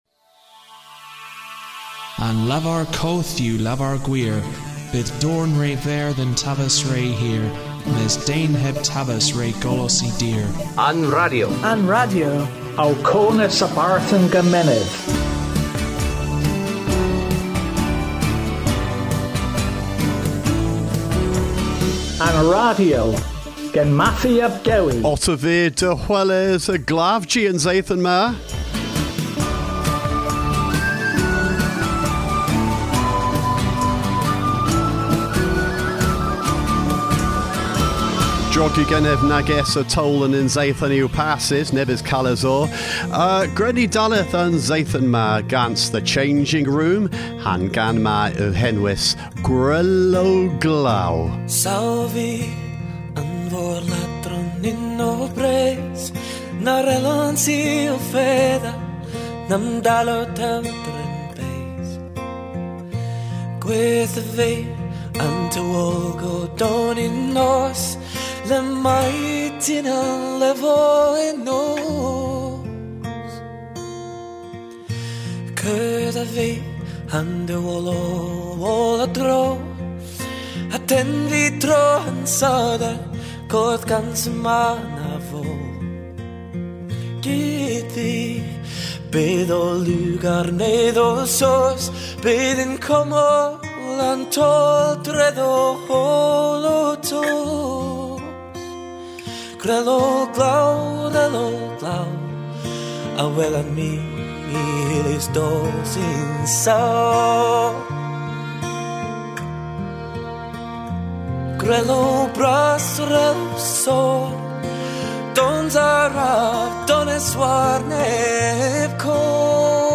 Weekly Cornish-language podcast